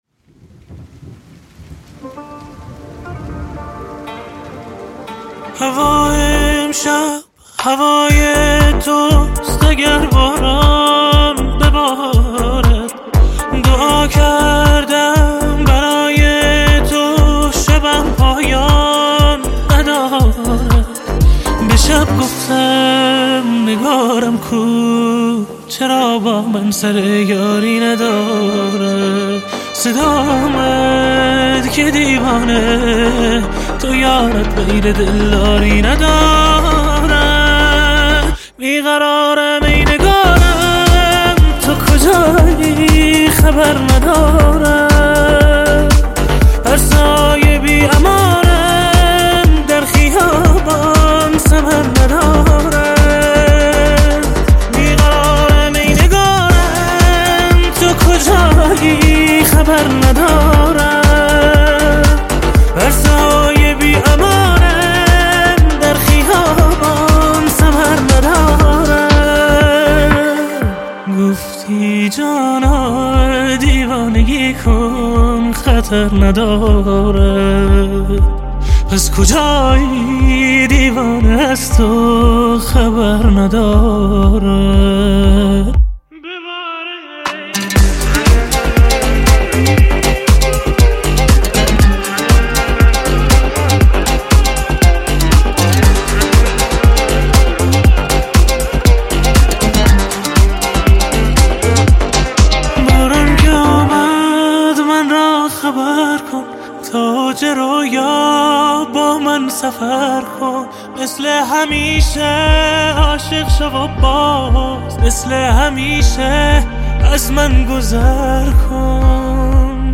پاپ غمگین عاشقانه عاشقانه غمگین